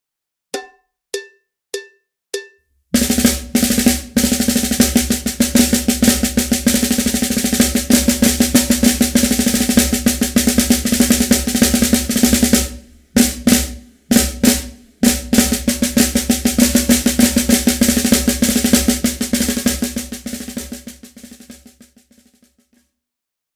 Besetzung: Schlagzeug
Rudimental-Solos